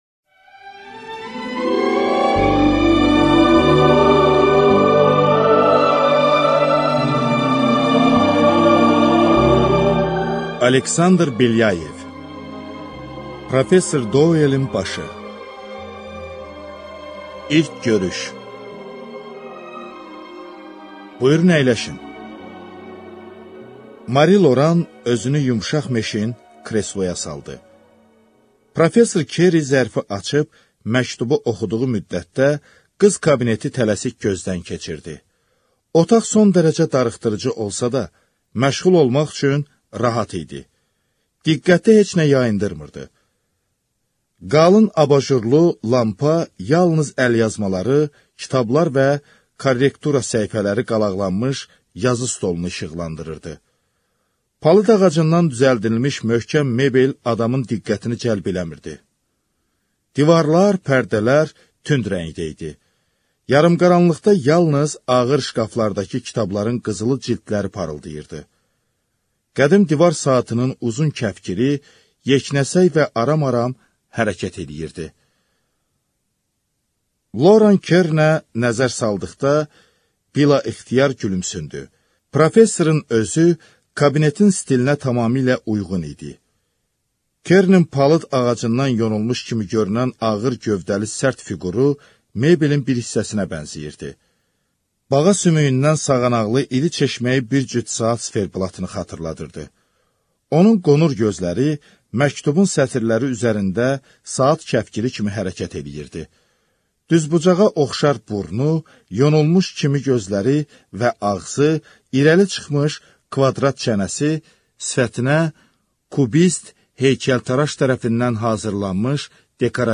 Аудиокнига Professor Douelin başı | Библиотека аудиокниг